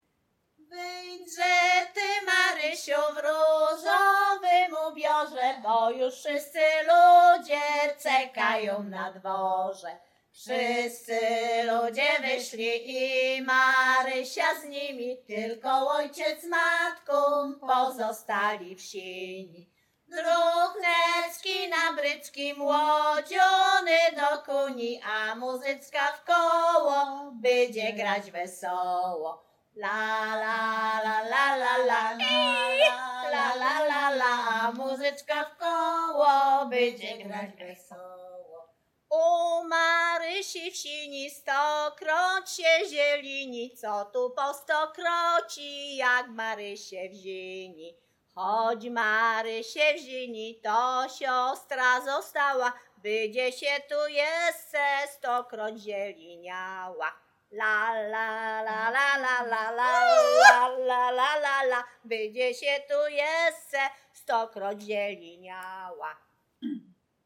Śpiewaczki z Chojnego
Sieradzkie
Weselna
wesele weselne